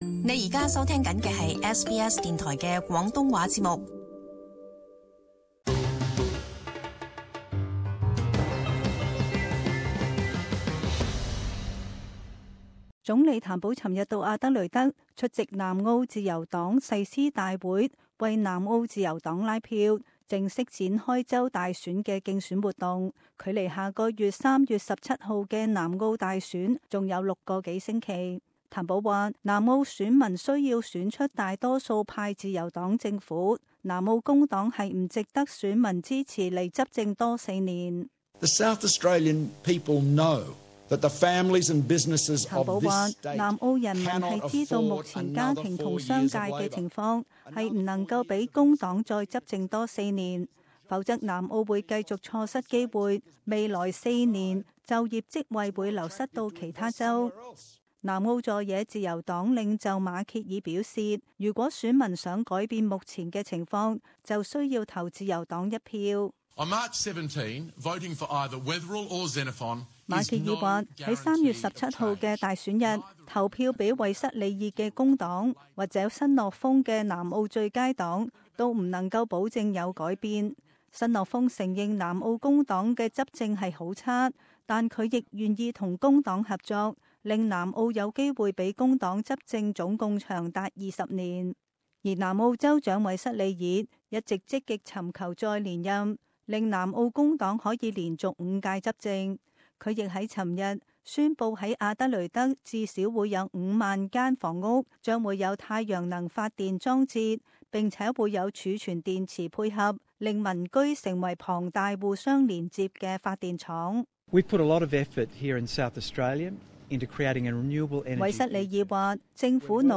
【時事報導】南澳大選